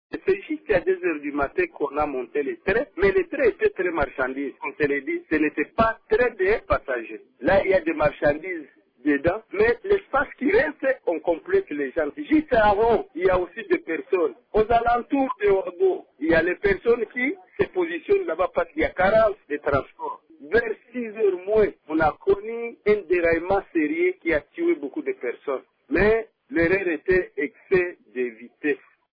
Un rescapé joint au téléphone par Radio Okapi indique que le train marchandise était surchargé: